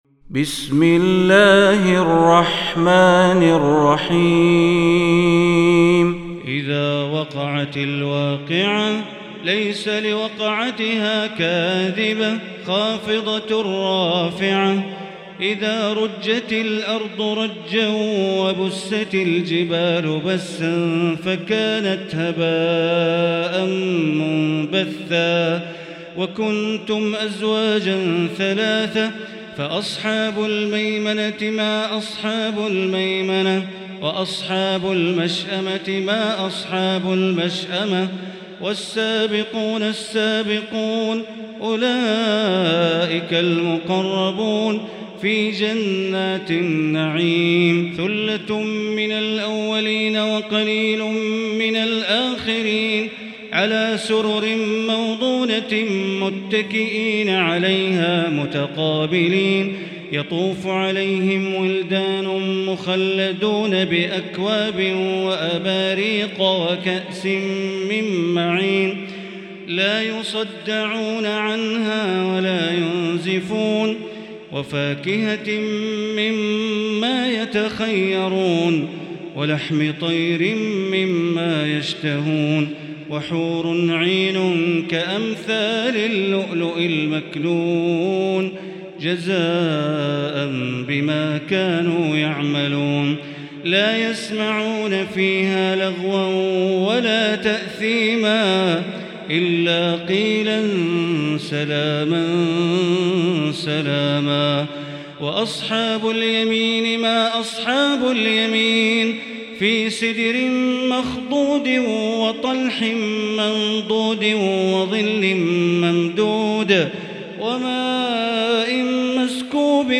المكان: المسجد الحرام الشيخ: معالي الشيخ أ.د. بندر بليلة معالي الشيخ أ.د. بندر بليلة الواقعة